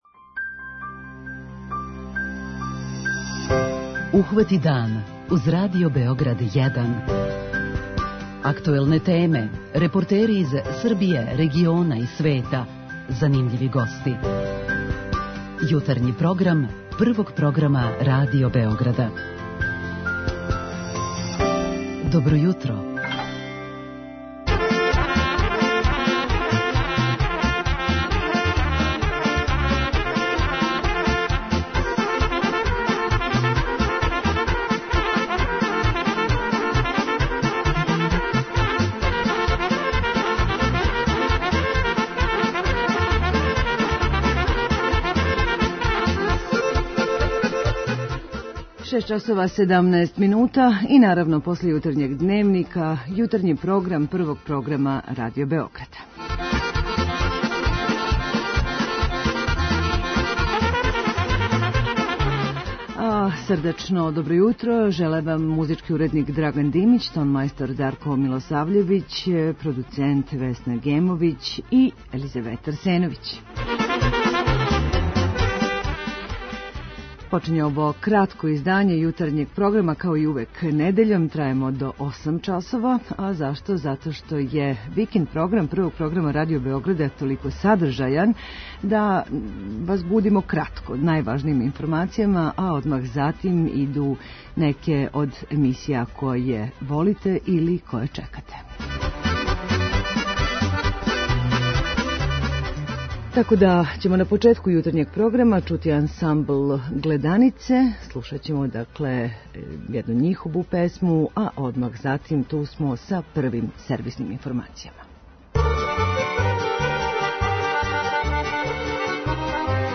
Овог недељног јутра будимо Вас уз добру народну музику, најважније сервисне информације, а позивамо Вас и да се - уколко желите - одазовете на фото конкурс 'Моја Западна Србија' који до половине октобра организује туристичка организација те регије, као и да пођете на ретроспективу урбаног баштованства Новог Сада - прву од шест 'Шетњи кроз наслеђе' коју организује Фондација 'Нови Сад 2121 - Европска престоница културе'.
преузми : 18.63 MB Ухвати дан Autor: Група аутора Јутарњи програм Радио Београда 1!